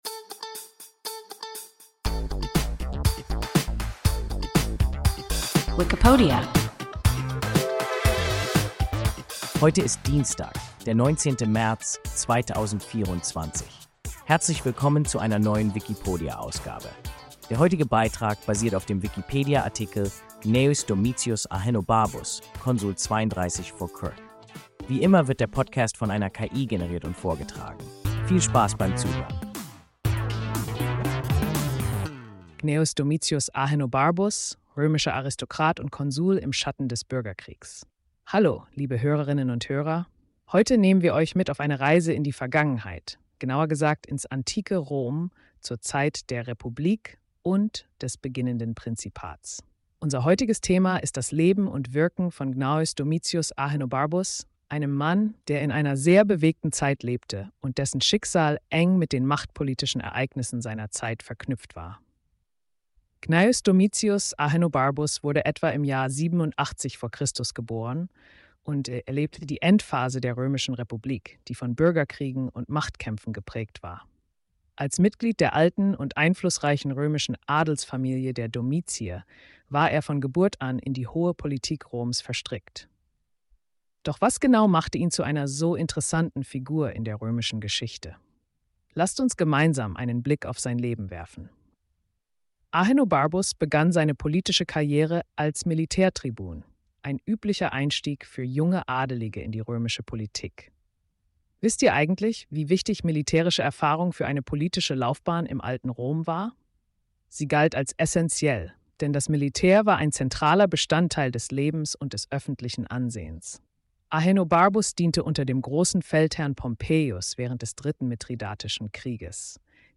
Wikipodia – ein KI Podcast Gnaeus Domitius Ahenobarbus (Konsul 32 v. Chr.)